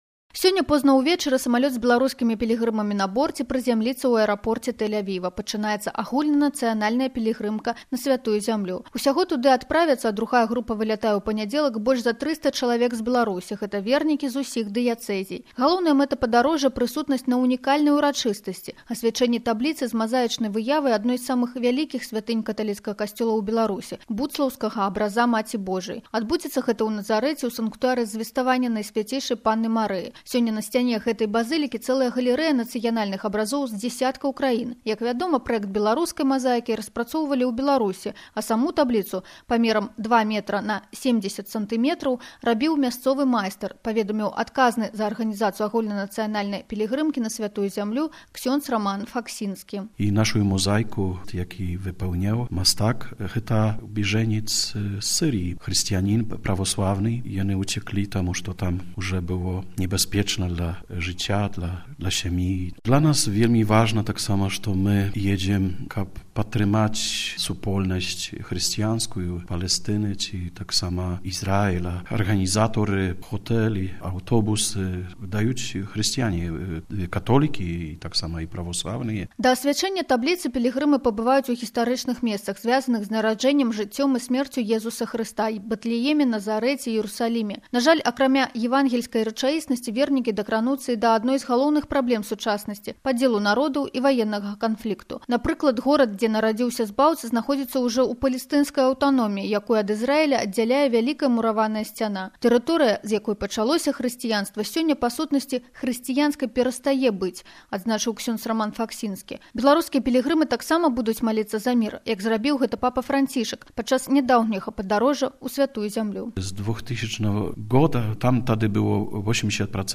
Вельмі важна, што мы едзем падтрымаць хрысціянскую супольнасць Палестыны і Ізраілю, бо пражыванне, пераезд – усё гэта прадастаўляюць пілігрымам мясцовыя хрысціяне – католікі або праваслаўныя”, - сказаў святар у інтэрв’ю Беларускай рэдакцыі Ватыканскага радыё.